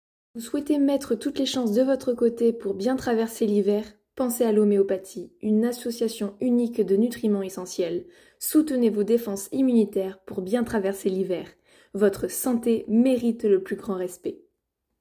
Voix off homéopathie